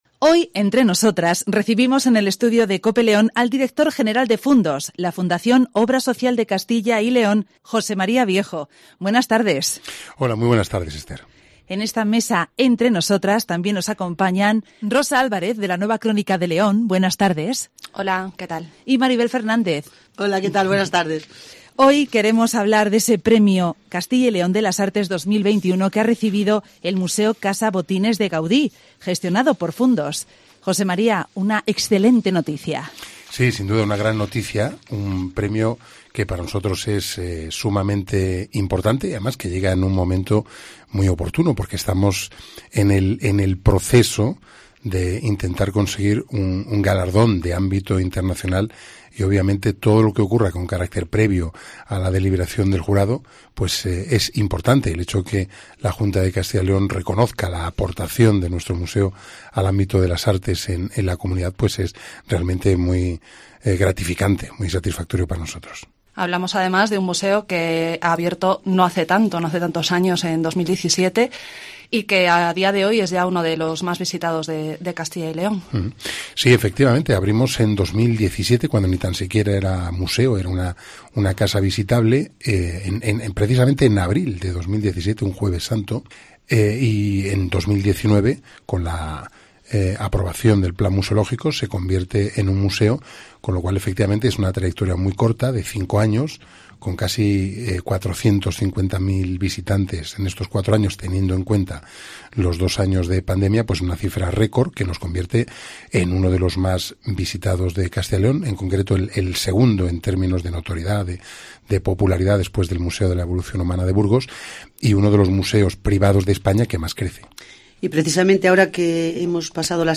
recibimos en el estudio de Cope León